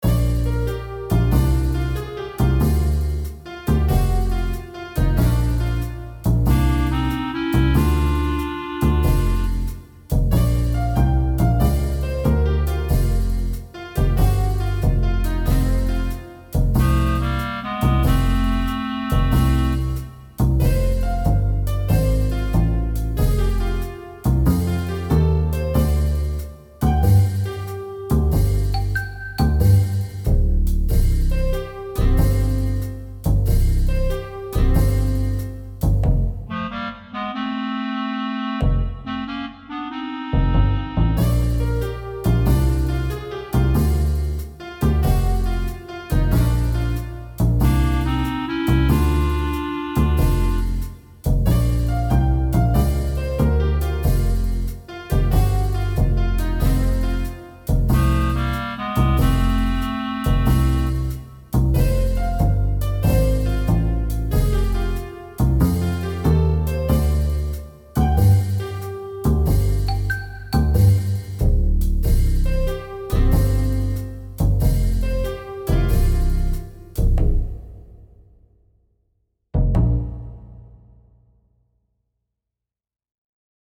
Jazz
Off-Vocal version of public domain song
but with a more playful, cheeky feel.